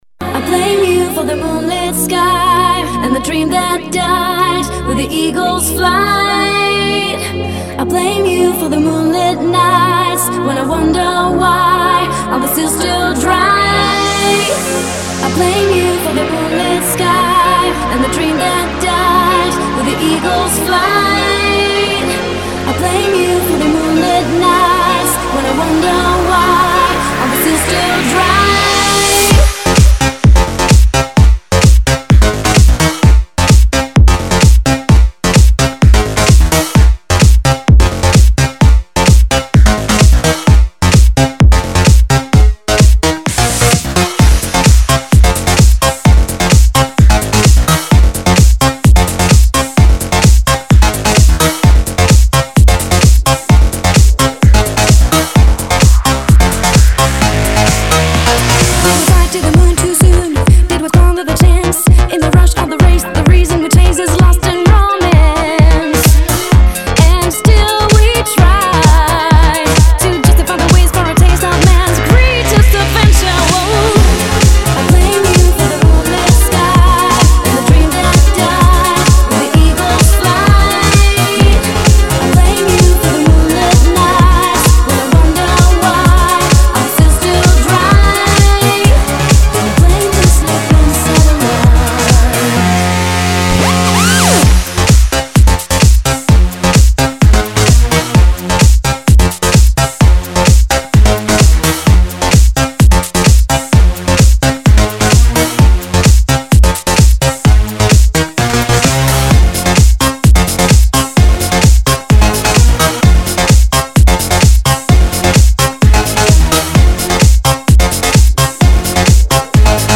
скачать клубную музыку
Категория: Club - Mix